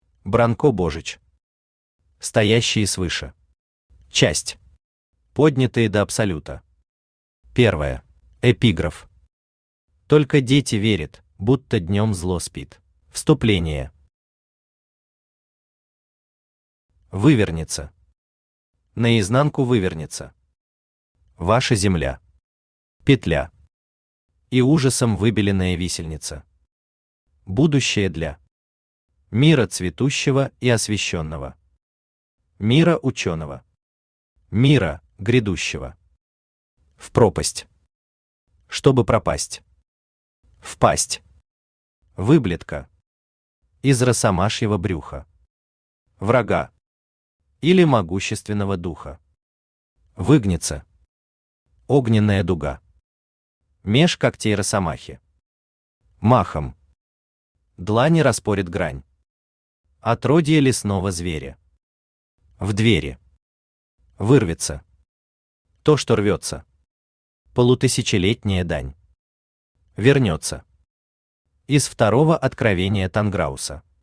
Аудиокнига Стоящие свыше. Часть I. Поднятые до абсолюта | Библиотека аудиокниг
Часть I. Поднятые до абсолюта Автор Бранко Божич Читает аудиокнигу Авточтец ЛитРес.